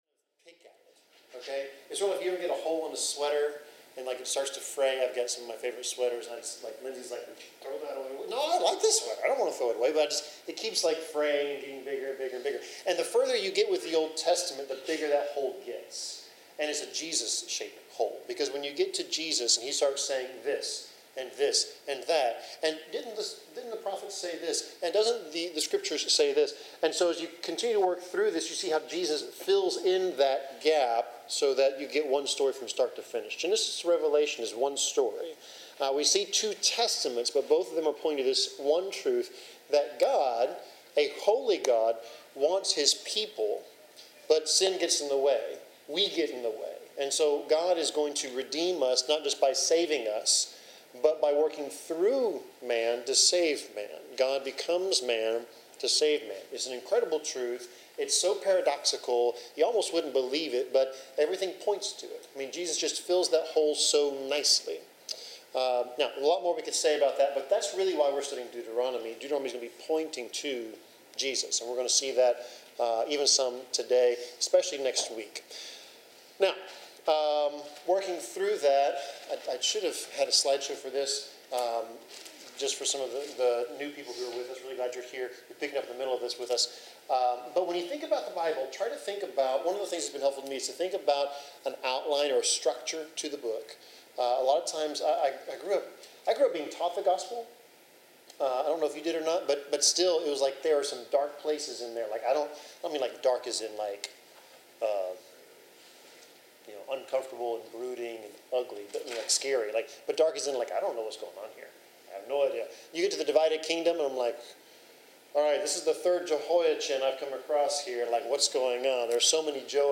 Service Type: Bible Class Topics: Covenant , Faith , Obedience , Promises of God